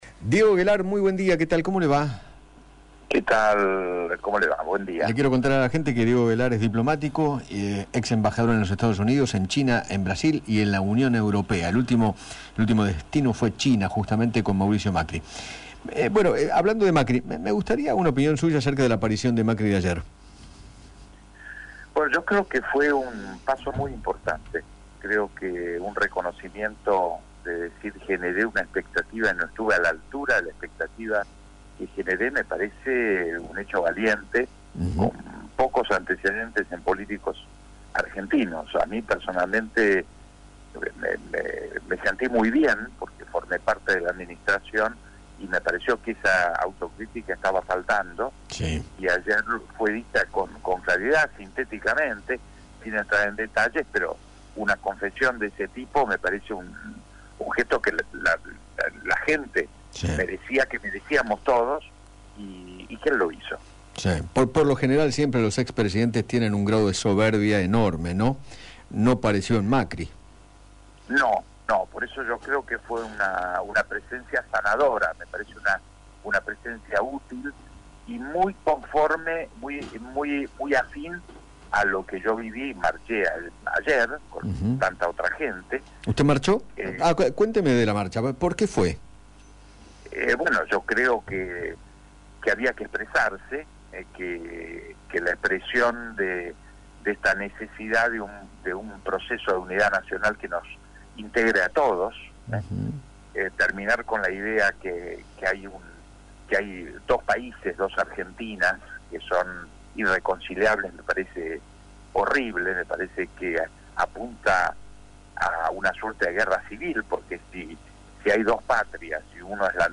El diplomático Diego Guelar, ex embajador argentino en los Estados Unidos, China, Brasil y en la Unión Europea, dialogó con Eduardo Feinmann sobre la aparición del ex presidente en la televisión argentina, en el mismo día en que se realizó un banderazo contra el actual Gobierno.